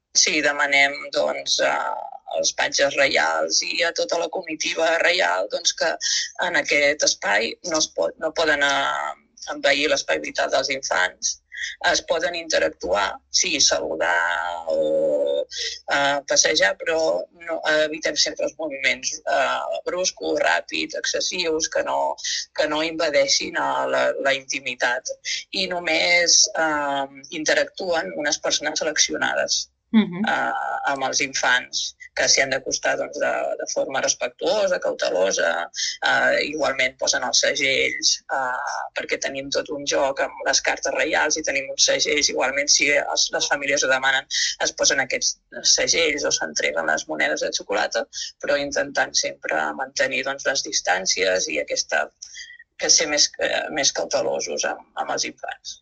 La regidora explica algunes de les adaptacions que es fan en aquest primer tram de la cavalcada.